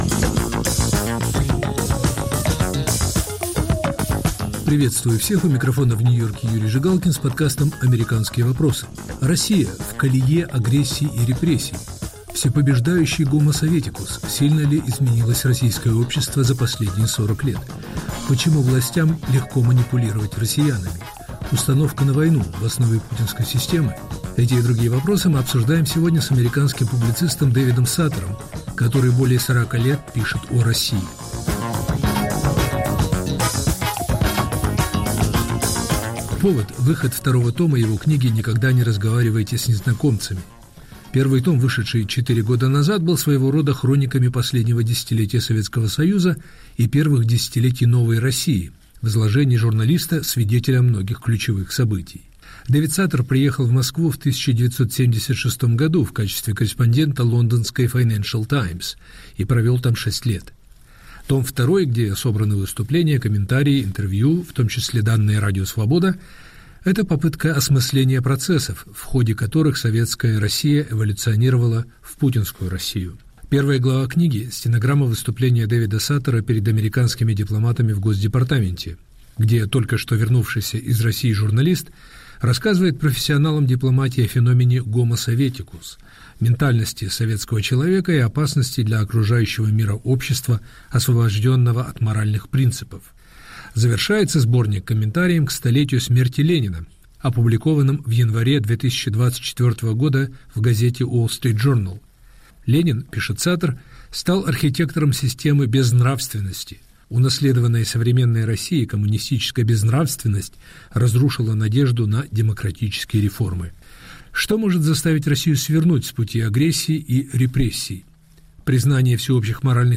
Разговор с Дэвидом Саттером о его новой книге